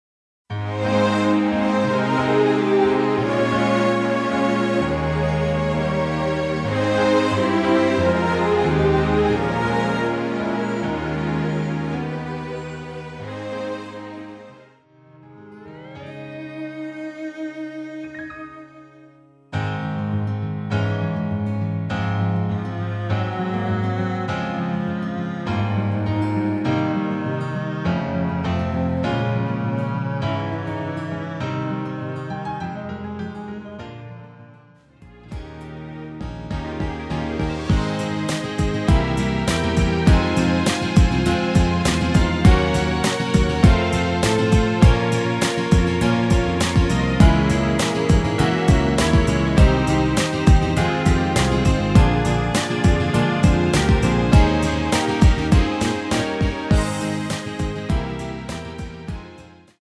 Ab
앞부분30초, 뒷부분30초씩 편집해서 올려 드리고 있습니다.